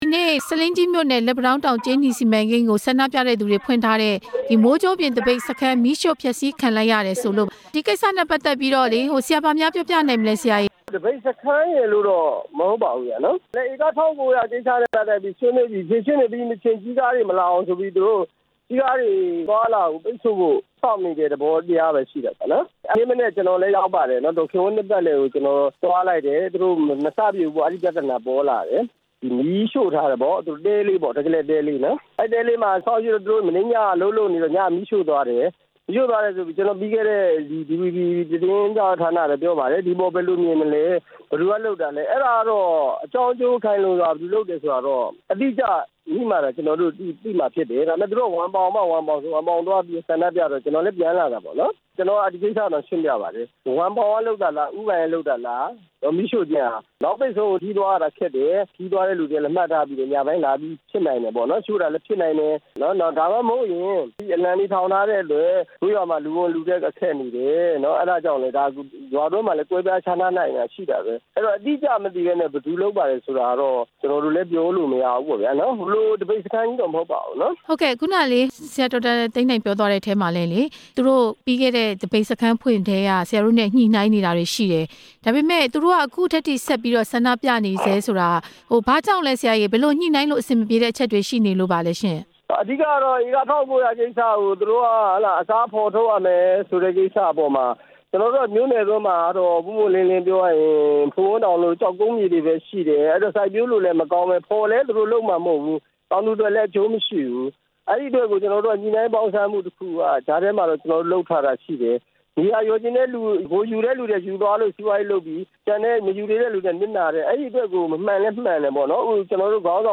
ကြေးနီစီမံကိန်း သပိတ်စခန်း မီးရှို့ဖျက်ဆီးခံရမှု ဒေသခံအမတ်နဲ့ မေးမြန်းချက်